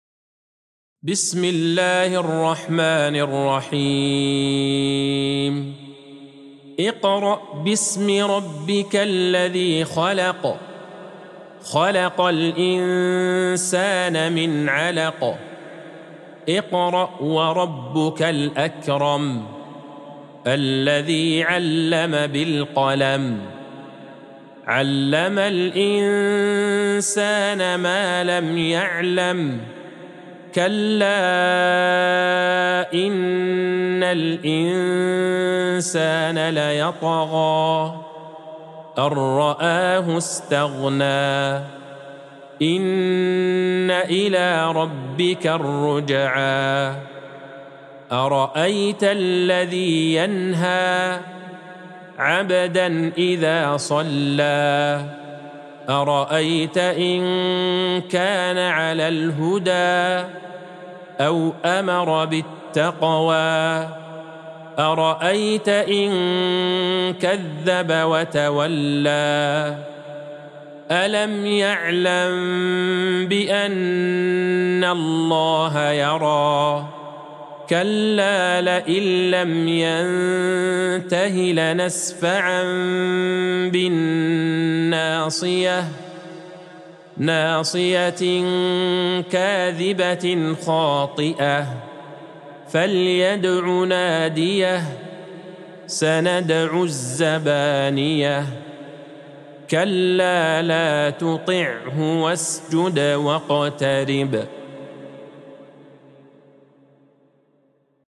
سورة العلق Surat Al-Alaq | مصحف المقارئ القرآنية > الختمة المرتلة ( مصحف المقارئ القرآنية) للشيخ عبدالله البعيجان > المصحف - تلاوات الحرمين